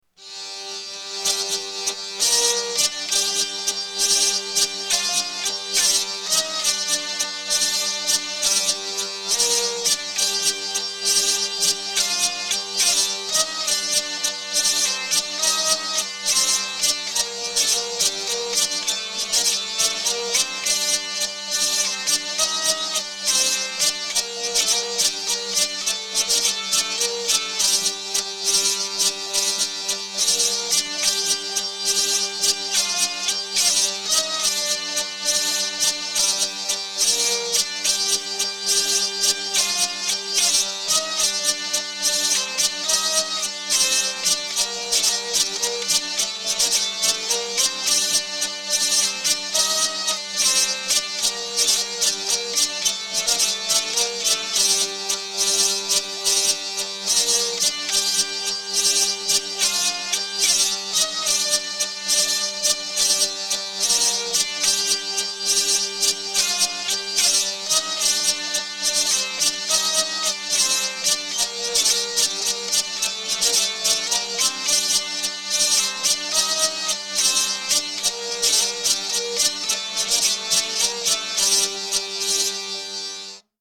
Drehleier Zusammenspiel
Ca Ira (Walzer
(Titel 15 und 16) G-Moll PDF